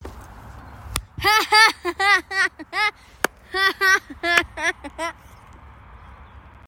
Hahaha Funny Efecto de Sonido Descargar
Hahaha Funny Botón de Sonido